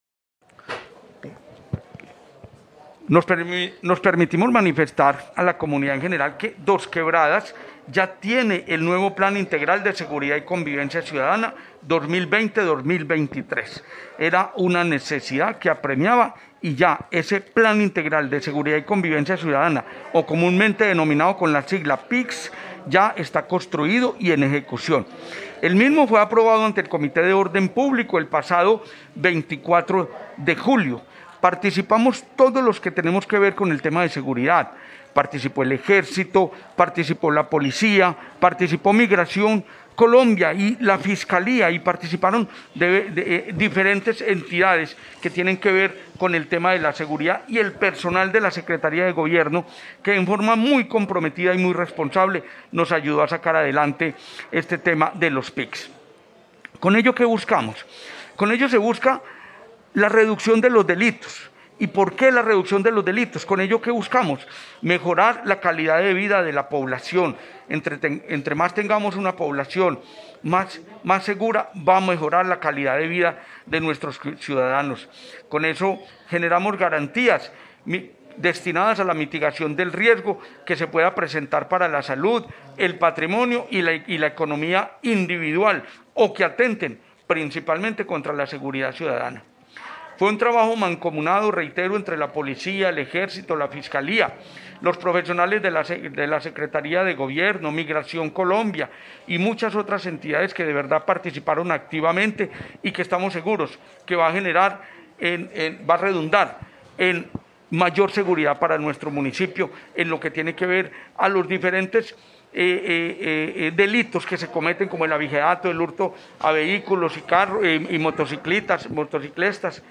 Comunicado-552-Audio-Sec-Gobierno-Juan-Carlos-Sepúlveda-Montoya.mp3